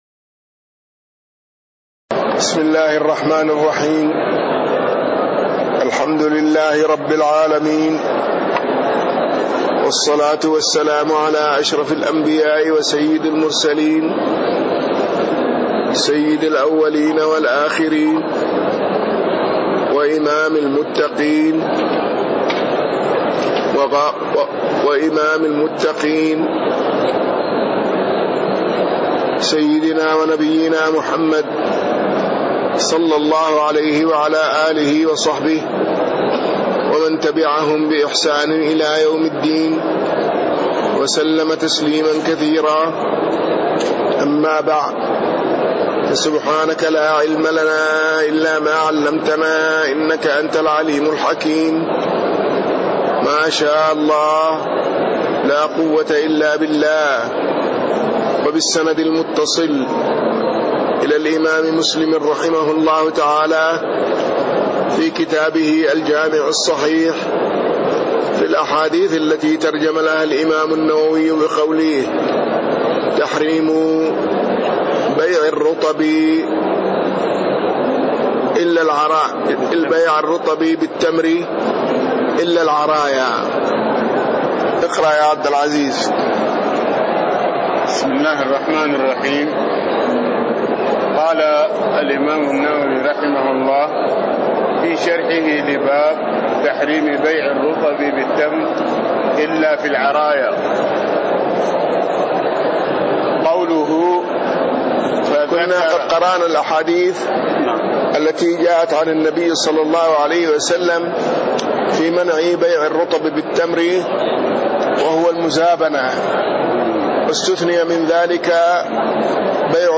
تاريخ النشر ١٢ ذو القعدة ١٤٣٤ هـ المكان: المسجد النبوي الشيخ